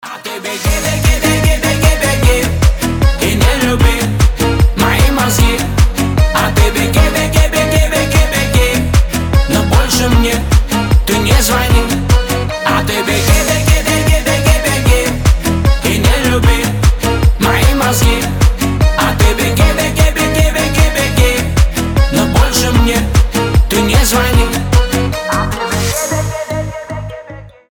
• Качество: 320, Stereo
гитара
быстрые